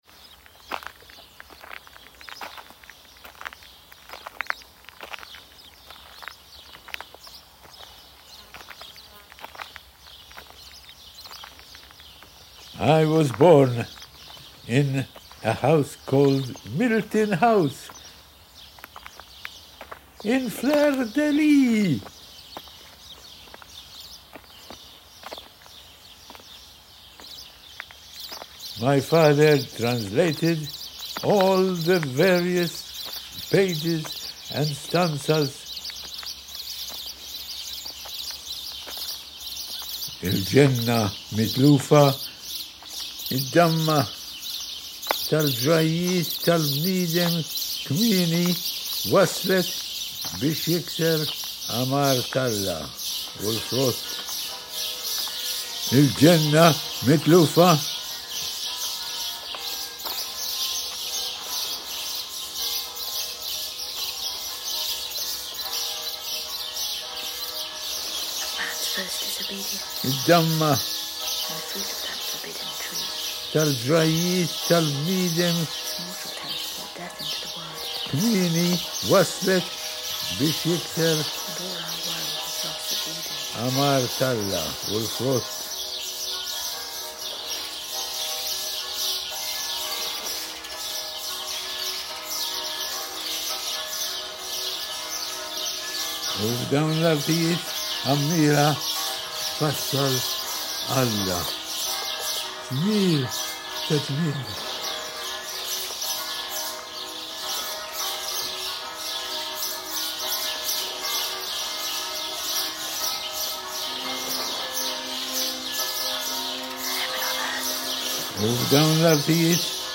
Small birds, mainly sparrows, have now made their home among the high eaves of these shelters, their calls echoing through the temple. This spot is unusually far from the traffic and urban noise that dominates much of the island, and I was able to record the bird sounds as I walked up the stony cliff path towards the temple.
My piece merges my own field recordings of these three quintessential Maltese sounds with something very personal.